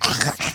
Minecraft Version Minecraft Version latest Latest Release | Latest Snapshot latest / assets / minecraft / sounds / entity / rabbit / attack4.ogg Compare With Compare With Latest Release | Latest Snapshot